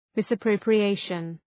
Shkrimi fonetik{,mısə,prəʋprı’eıʃən}
misappropriation.mp3